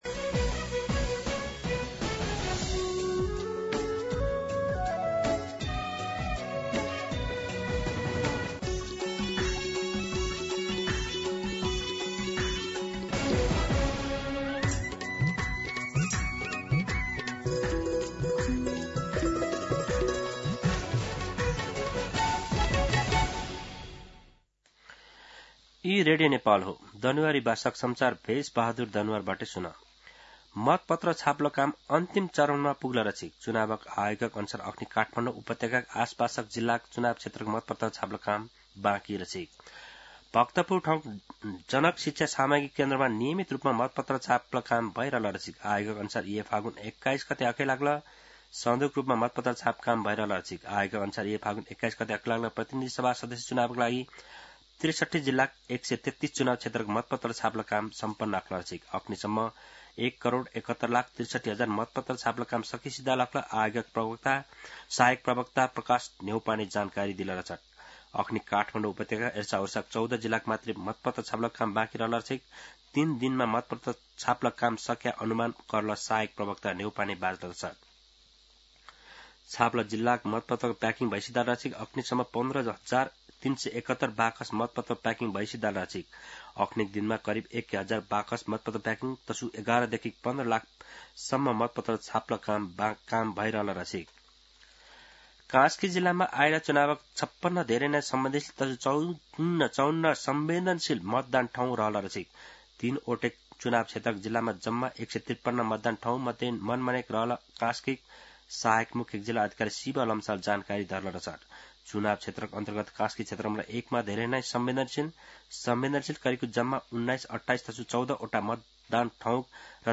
दनुवार भाषामा समाचार : २ फागुन , २०८२
Danuwar-News-02.mp3